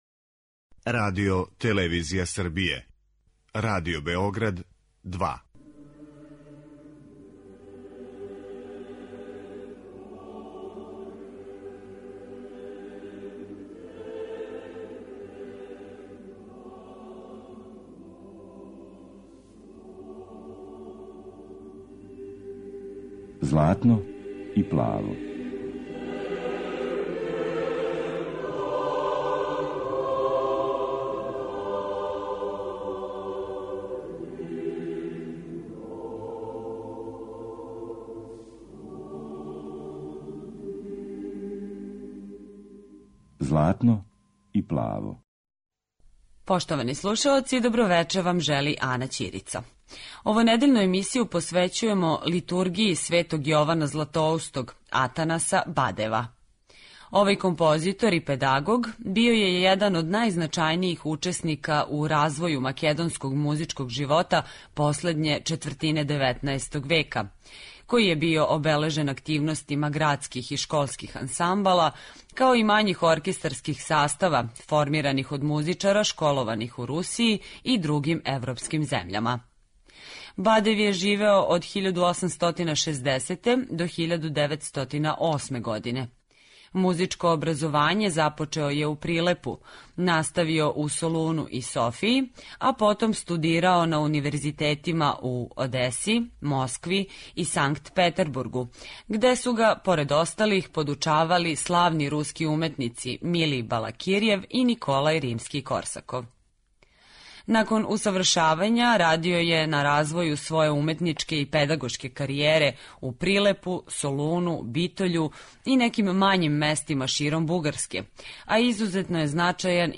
Емисија посвећена православној духовној музици.